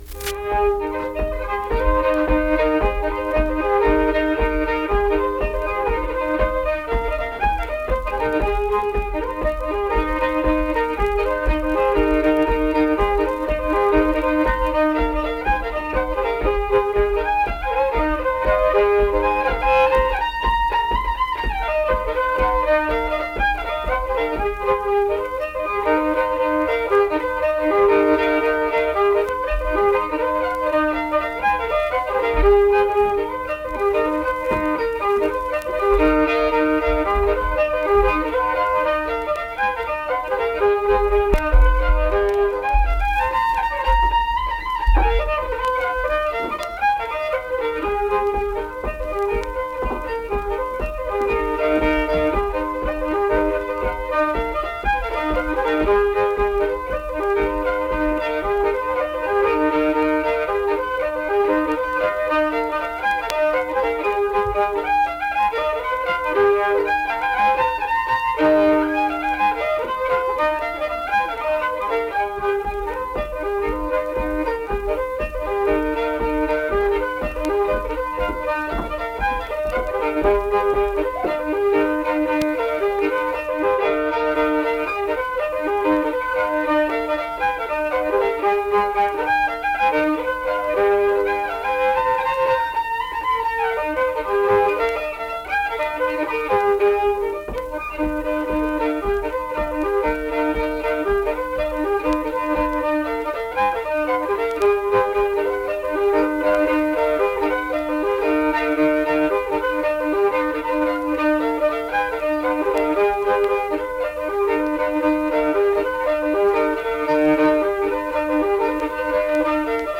Unaccompanied fiddle music
Instrumental Music
Fiddle
Marlinton (W. Va.), Pocahontas County (W. Va.)